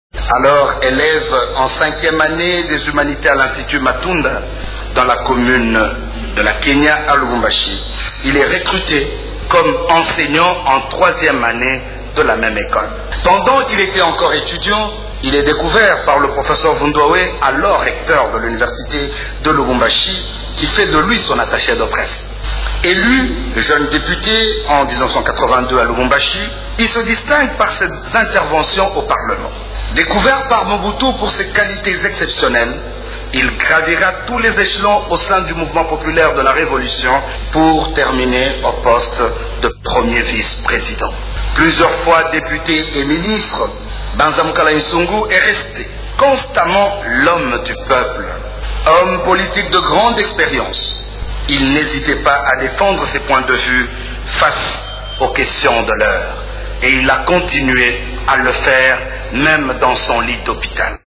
Dans son oraison funèbre, le ministre de la Santé, Felix Kabange Numbi, a rappelé le parcours de l’ancien ministre de la Culture.